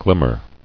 [glim·mer]